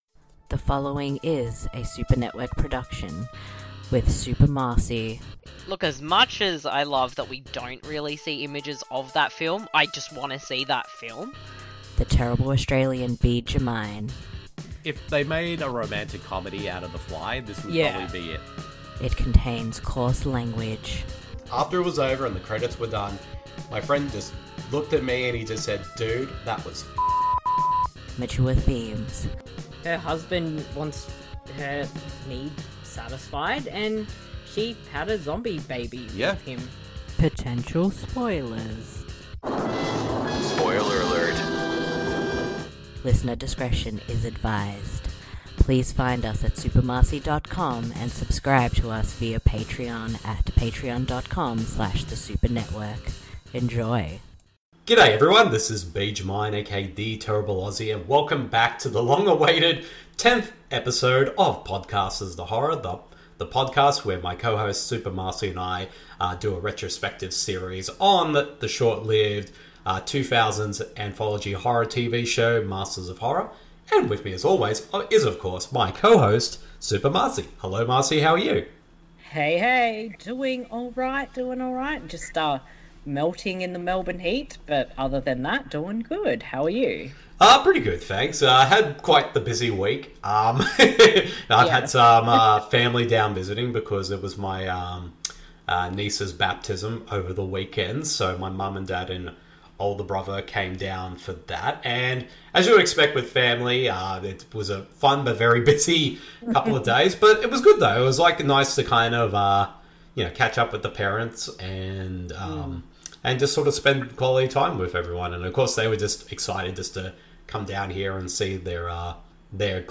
This mini-series will cover short reviews and discussion for every episode of Mick Garris’ horror anthology series Masters Of Horror !